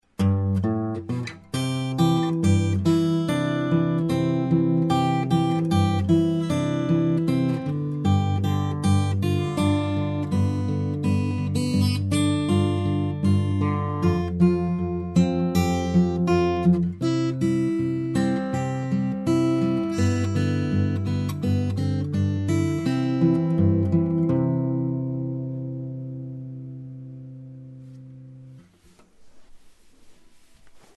Det är mestadels fjärdedelar och halvnoter som i övningen, men i takt 7 är det en triol.
Fingerstyle 4.mp3